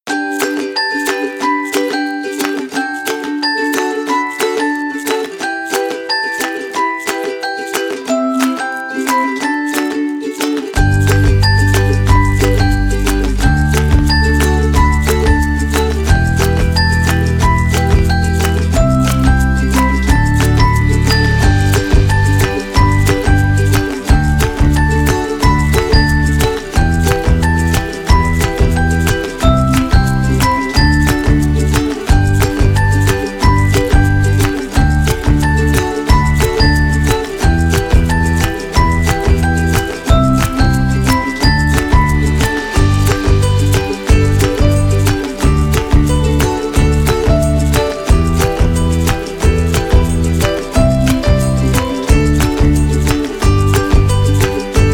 BGM Ringtones